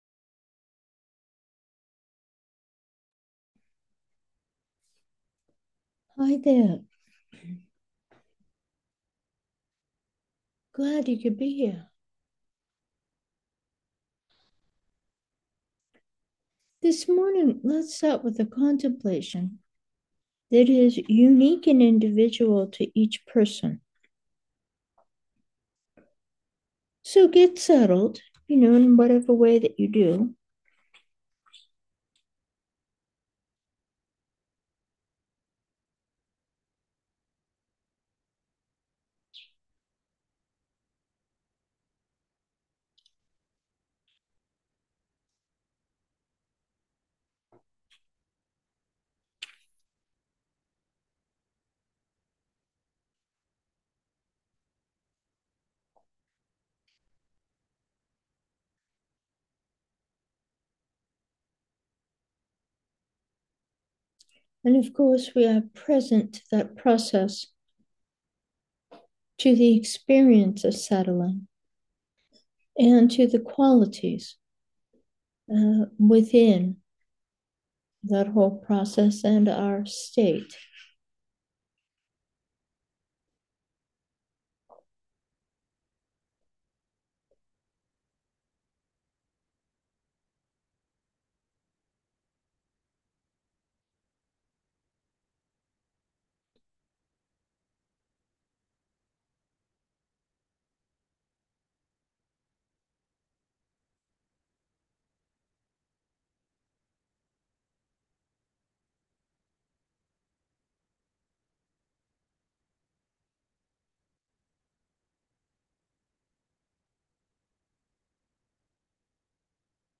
Meditation: joy 9, universal and specific